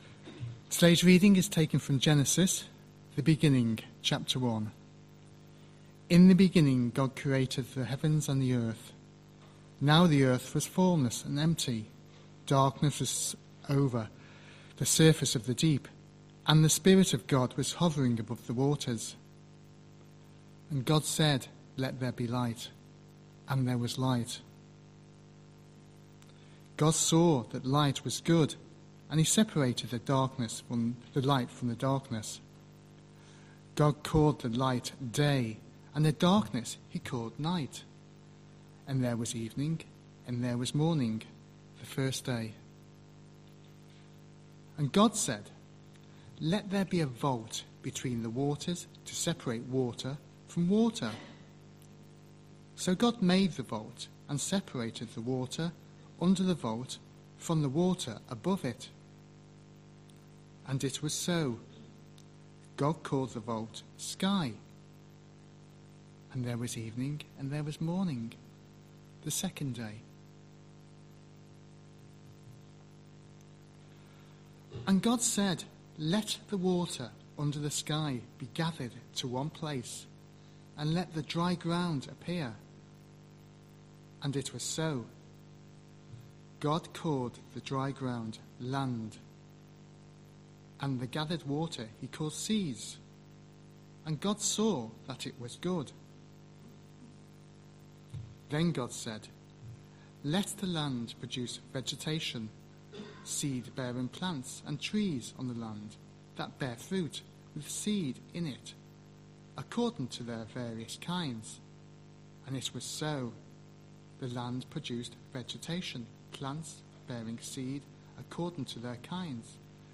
14th September 2025 Sunday Reading and Talk - St Luke's